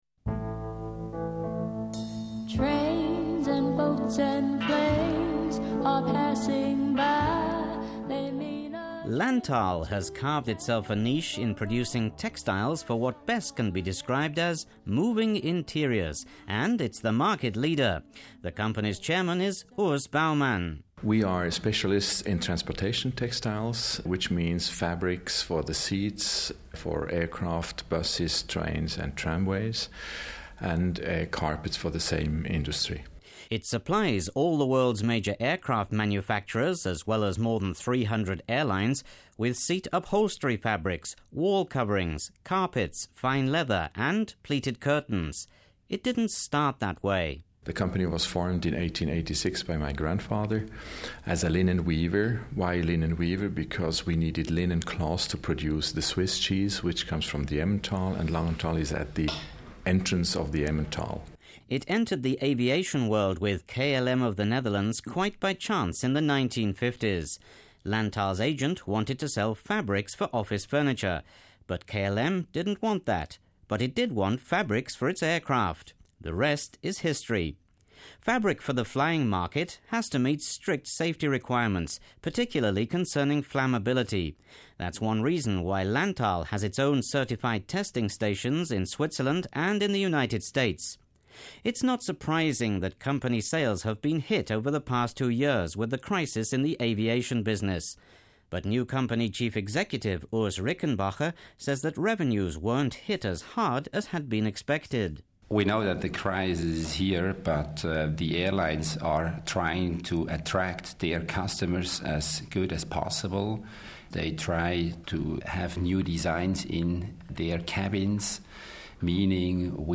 Fabric and carpet from a company in Langenthal (Lang-en-taal), canton Bern, travel around the world every day. And some pretty influential names use products from Lantal (Lan-taal) Textiles. United States president George W. Bush sits on Lantal fabric every time he flies in Air Force One. And Queen Elizabeth walks on the company’s carpet in her aircraft.